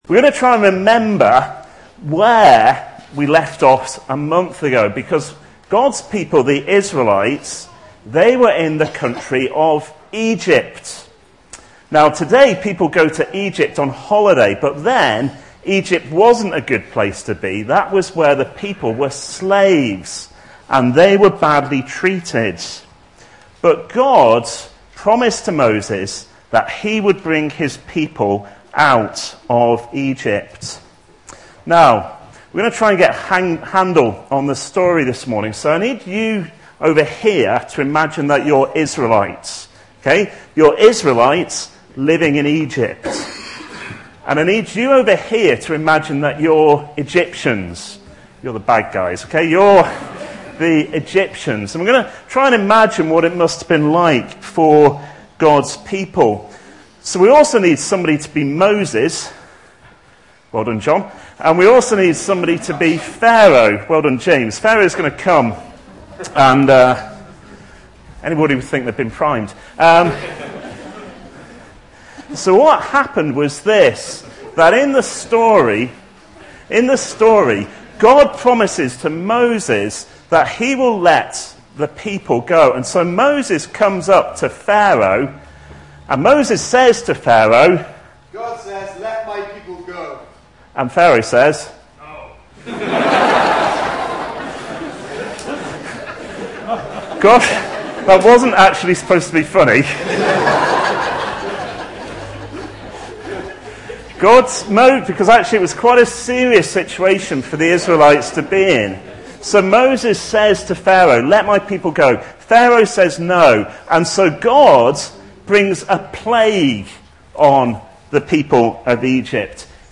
Preaching
Nostalgia (Psalm 129) Recorded at Woodstock Road Baptist Church on 30 May 2010.